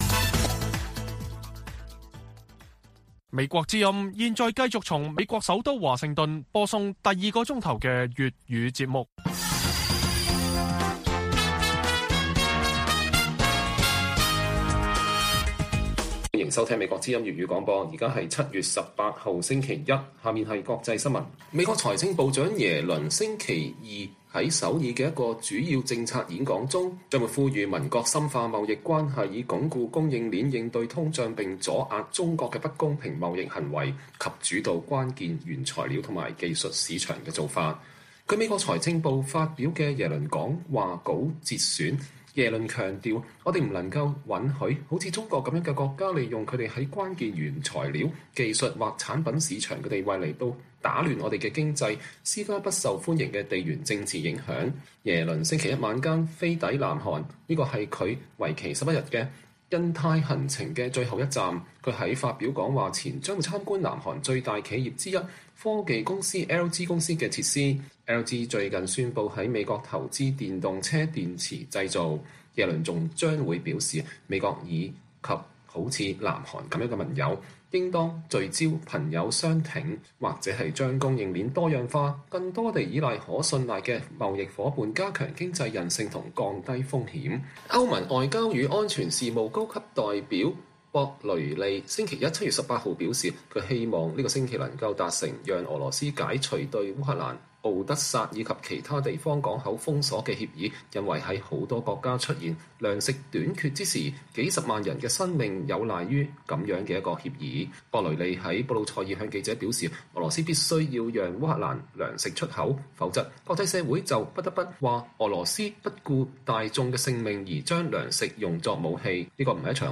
粵語新聞 晚上10-11點: 中共二十大臨近，“領袖”稱號進入快車道